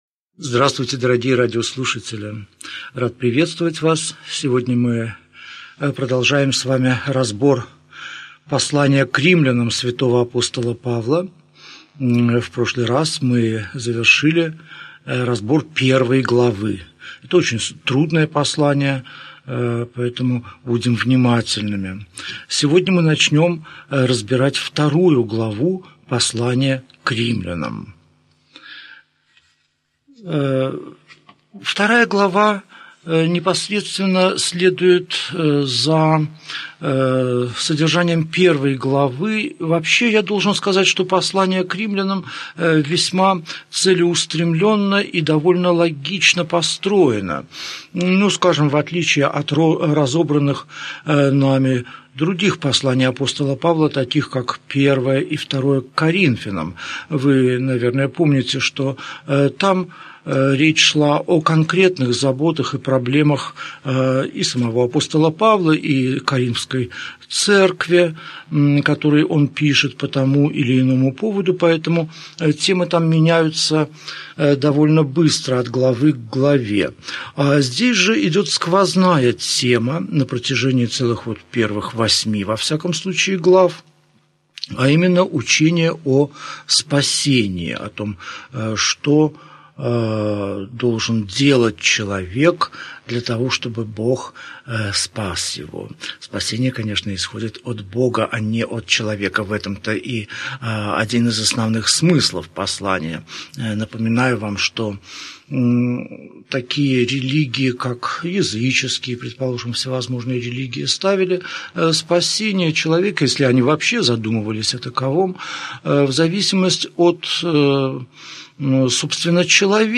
Аудиокнига Беседа 71. Послание к Римлянам. Глава 2, стих 1 – глава 3, стих 8 | Библиотека аудиокниг